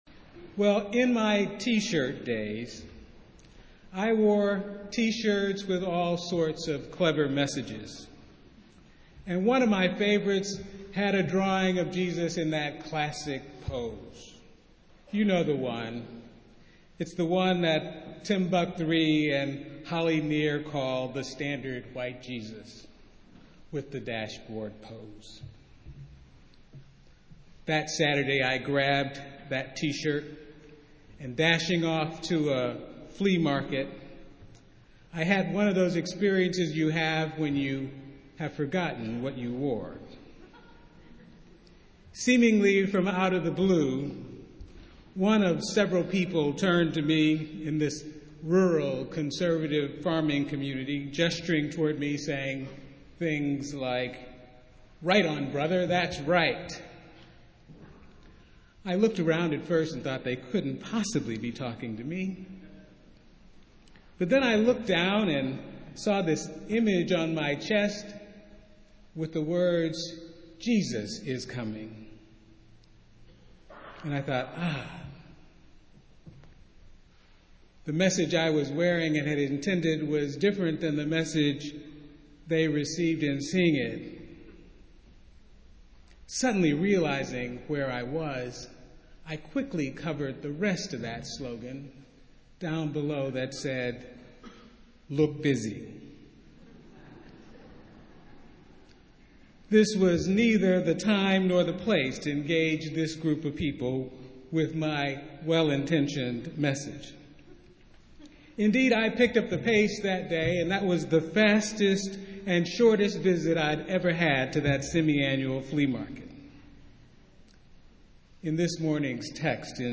Festival Worship - First Sunday in Advent